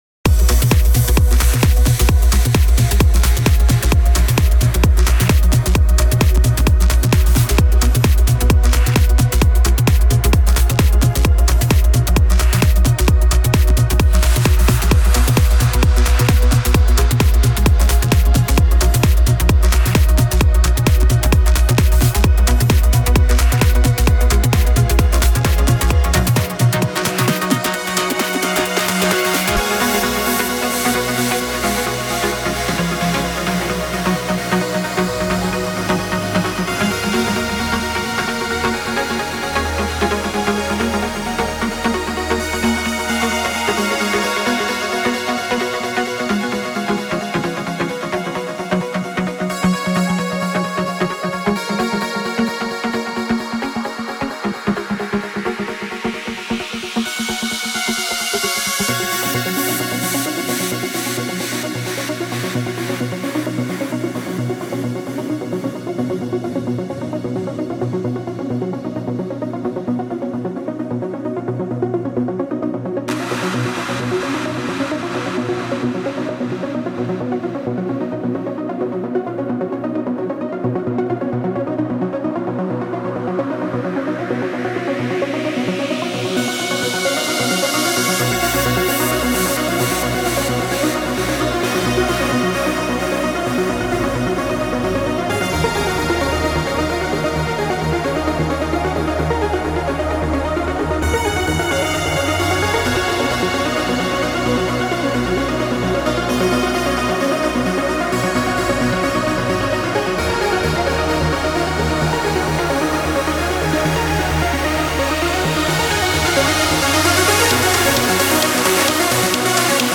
Категория: Trance